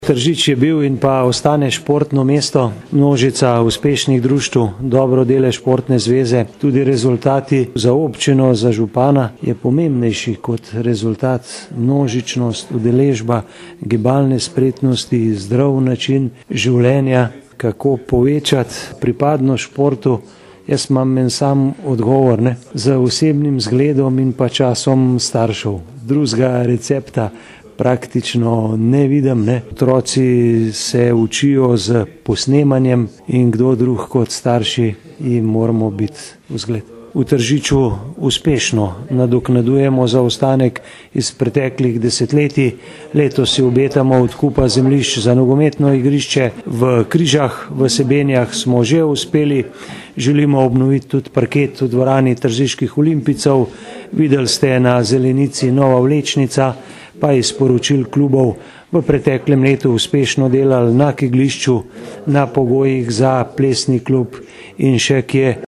81141_izjavazupanaobcinetrzicmag.borutasajovicaosportuvobcini.mp3